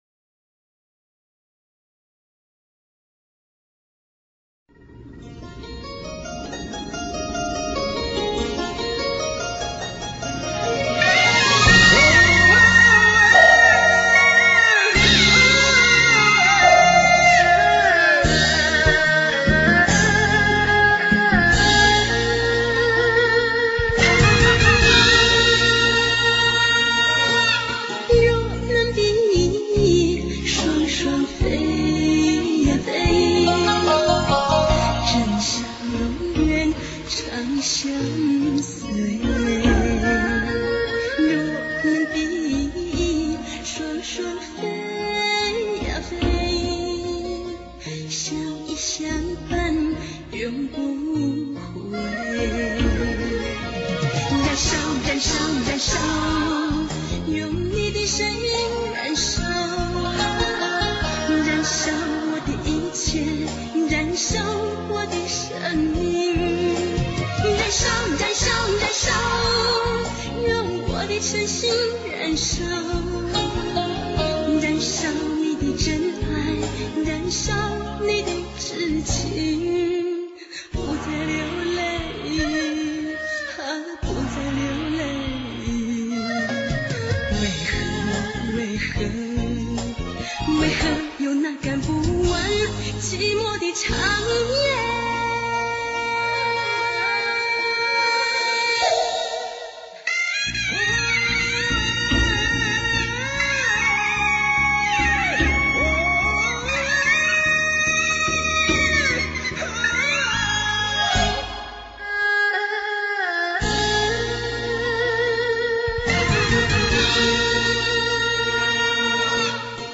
没有什么唢呐曲呀，唢呐只是音乐的一个引子？